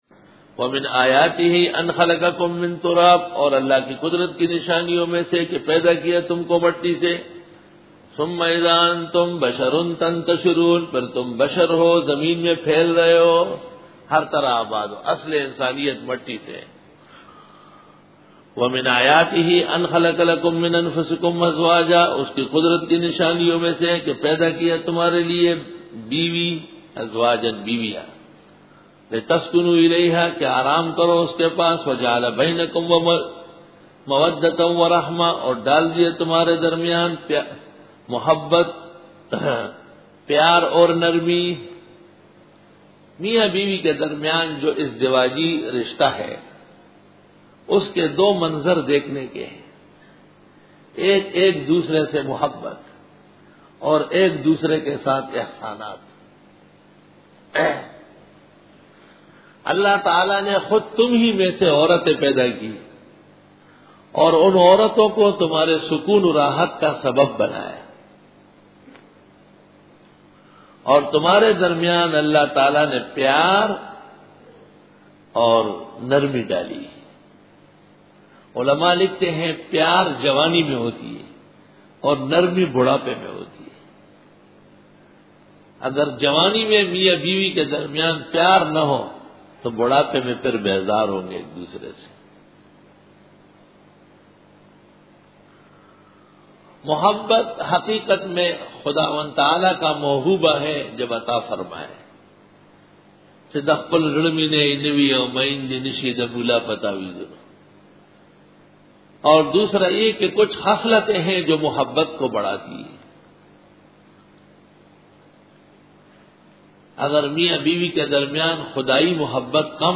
Bayan
Dora-e-Tafseer 2004